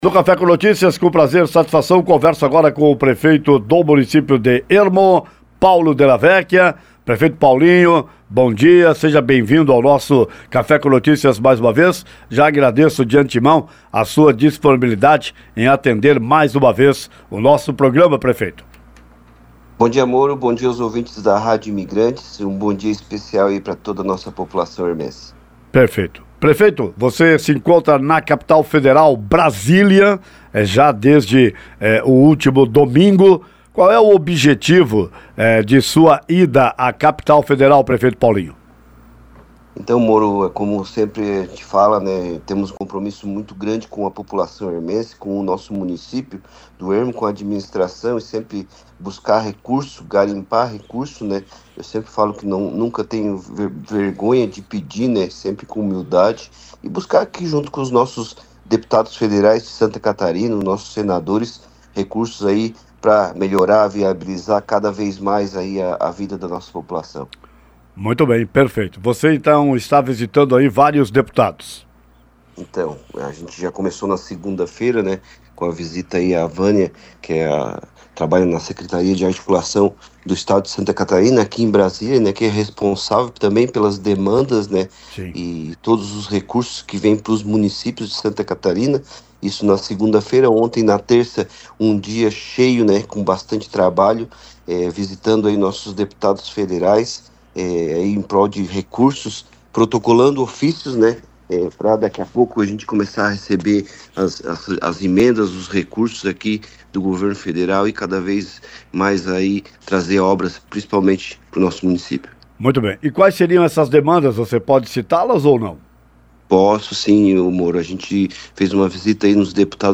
Prefeito de Ermo, Paulinho Della Vecchia está em Brasília em busca de recursos para o município: Nesta quarta-feira (26/02) ele concedeu entrevista no Programa Café com Notícias.
Entrevista-com-Prefeito-Paulinho-Dia-26-de-Fevereiro.mp3